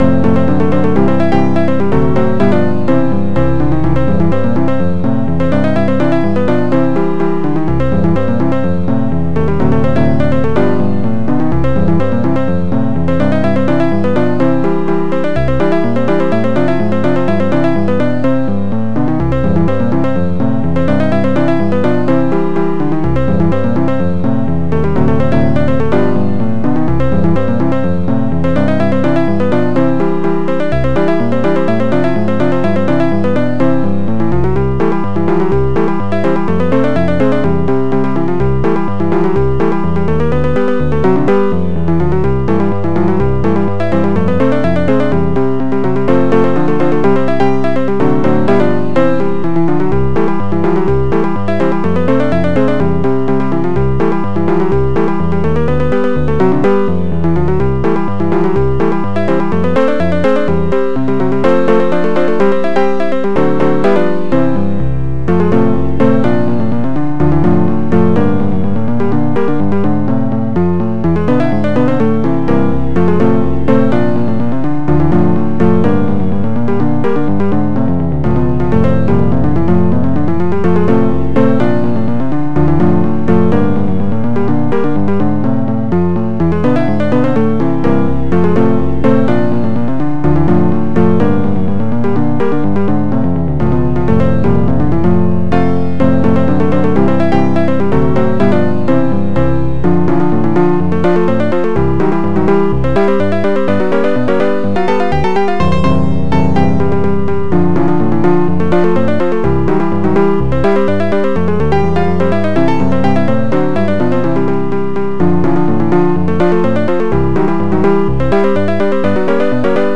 Digital Sound Interface Kit RIFF Module
Tracker DSIK DSM-format